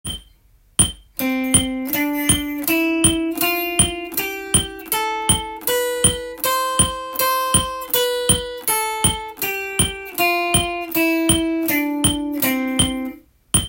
音階で裏拍練習
ドレミなどの音階でも裏拍練習しておくと
最初は簡単なドレミファソラシで弾いて
ペンタトニックスケールで弾いてみたり